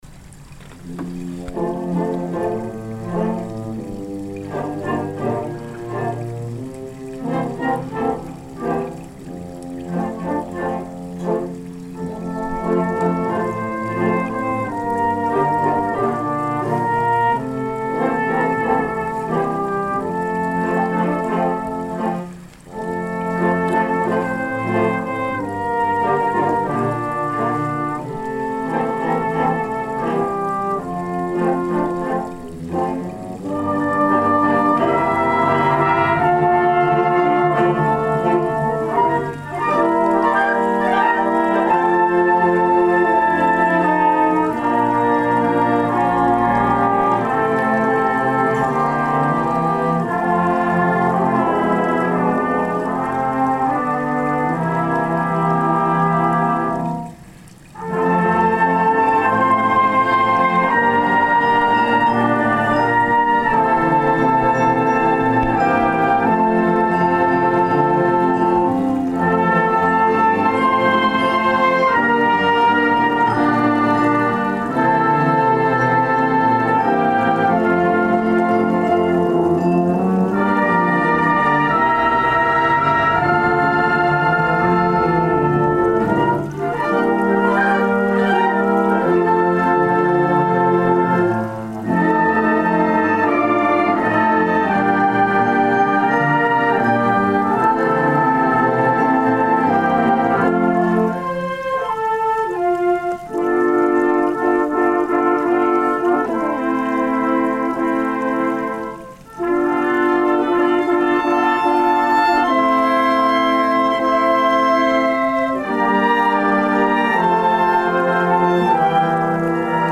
The Karl L. King Municipal Band of Fort Dodge, Iowa
The Final Performance of the 2017 Summer Concert Series
Next, the band performed Karl King’s 1914 Reverie, On a Summer's Eve.